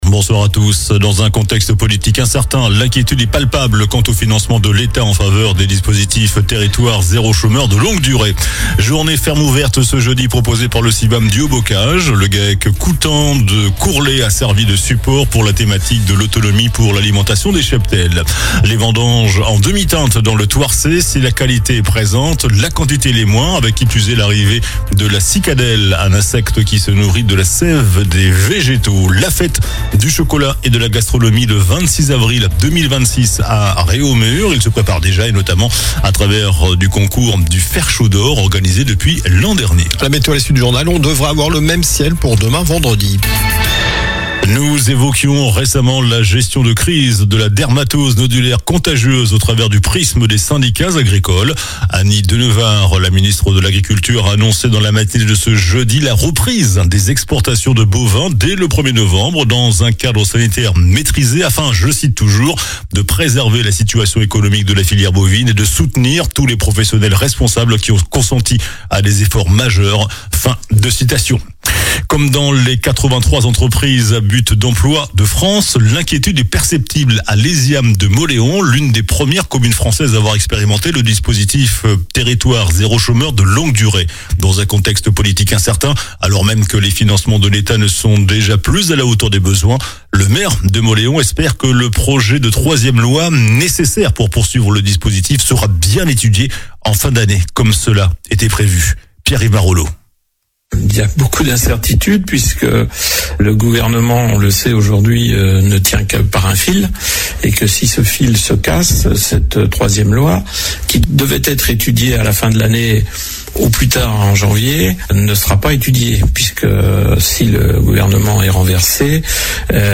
Journal du jeudi 30 octobre (soir)